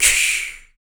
Index of /90_sSampleCDs/ILIO - Vocal Planet VOL-3 - Jazz & FX/Partition H/1 MALE PERC
CYMBAL 006.wav